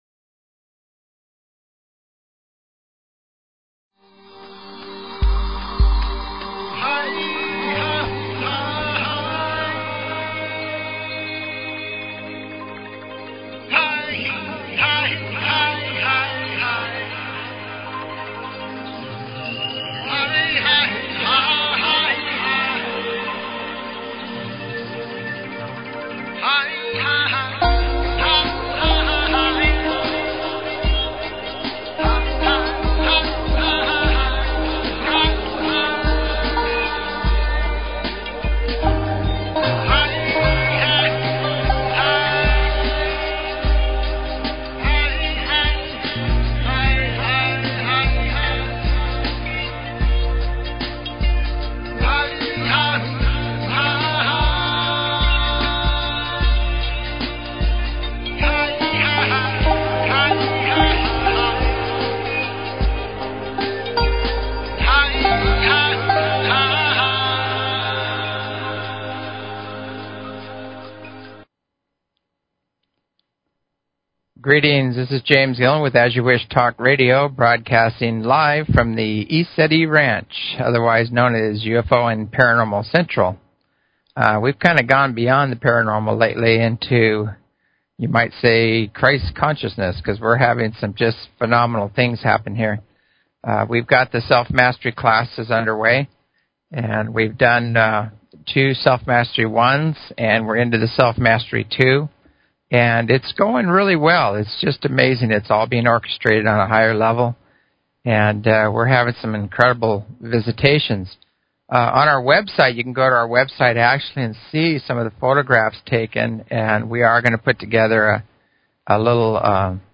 Talk Show Episode, Audio Podcast, As_You_Wish_Talk_Radio and Courtesy of BBS Radio on , show guests , about , categorized as
Self Mastery Workshop Weekend, Listen to the attendees and their experiences